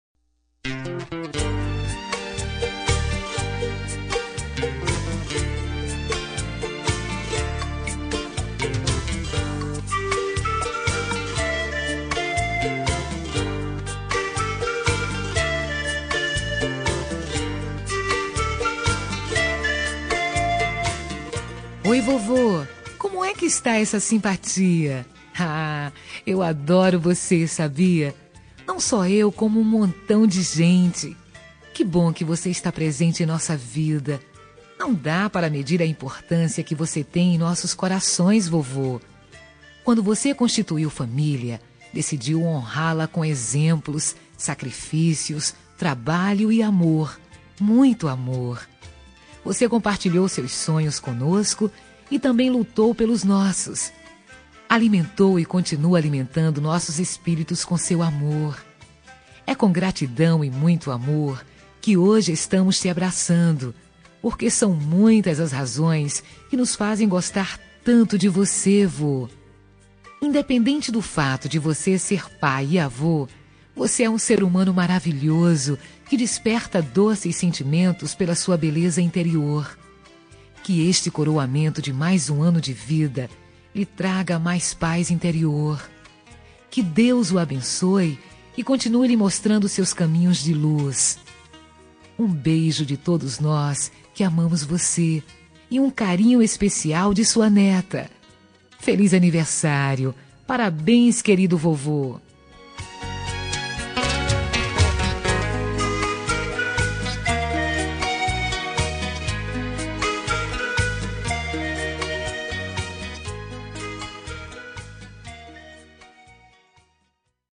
Aniversário de Avô – Voz Feminina – Cód: 2088